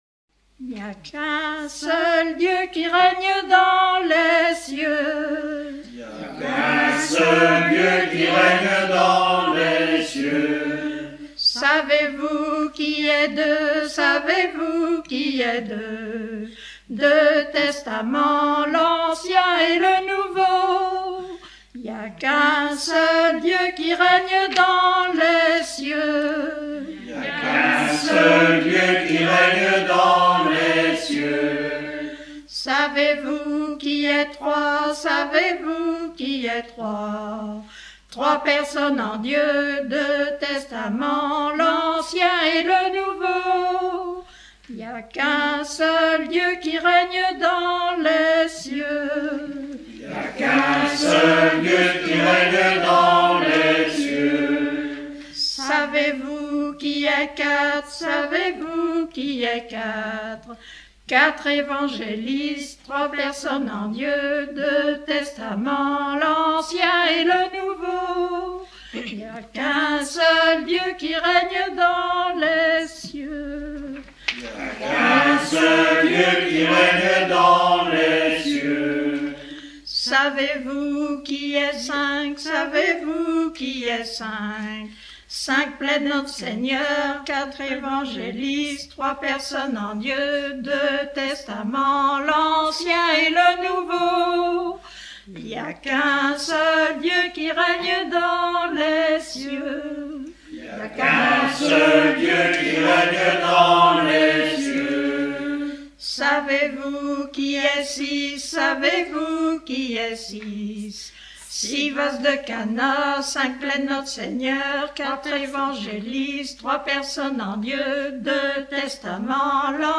chanson apprise à l'école dans les années 1930